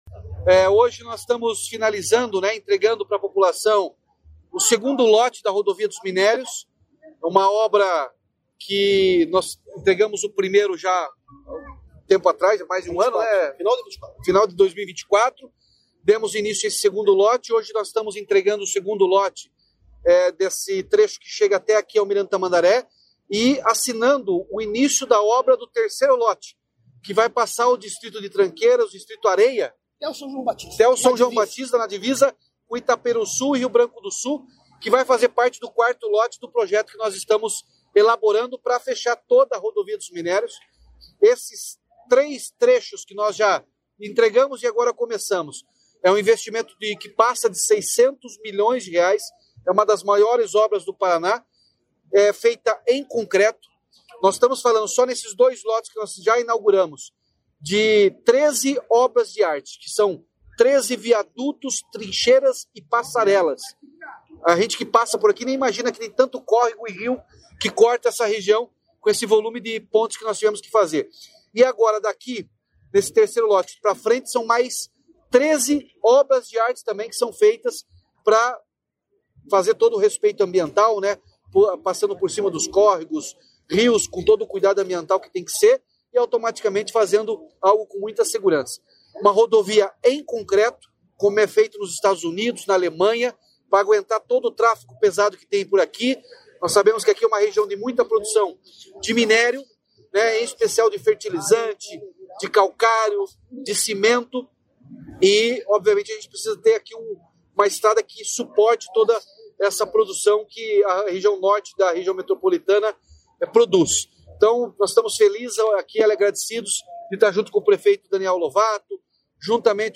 Sonora do governador Ratinho Junior sobre a entrega da duplicação do Lote 2 da Rodovia dos Minérios